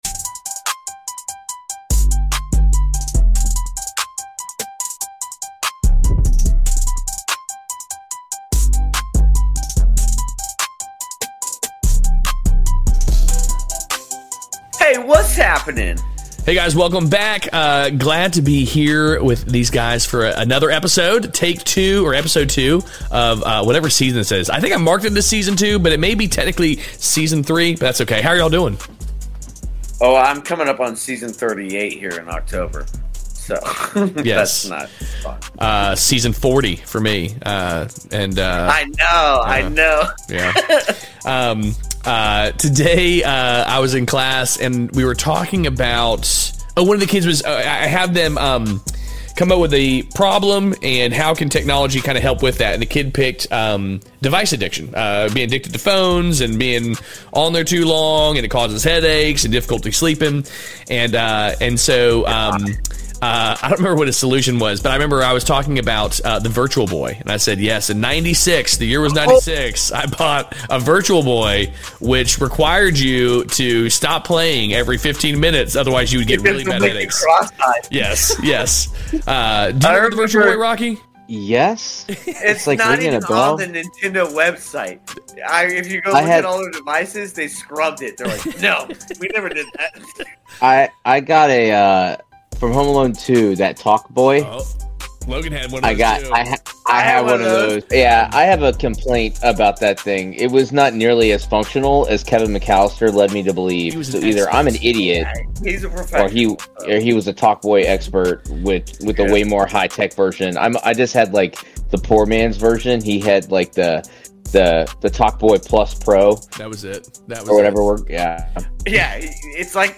Play Rate Listened List Bookmark Get this podcast via API From The Podcast Two brothers share their struggles with kids today while remembering the shenanigans that should have killed them as kids.